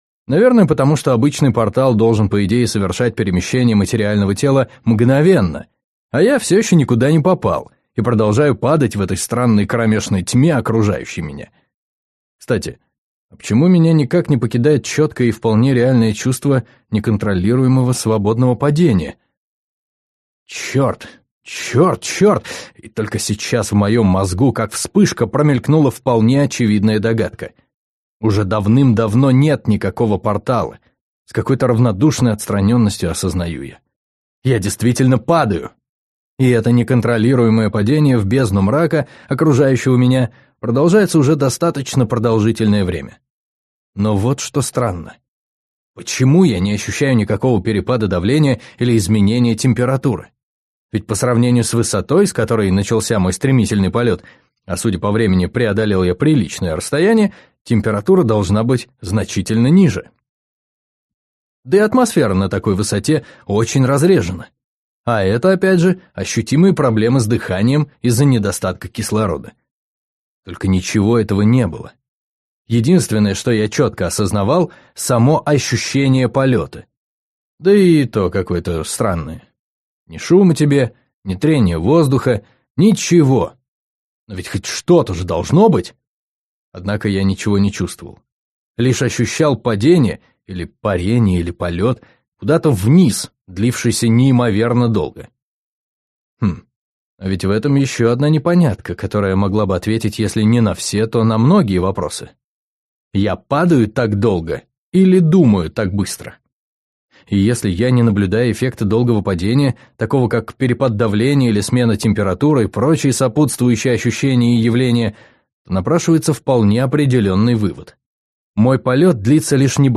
Аудиокнига Мир Тёмного солнца | Библиотека аудиокниг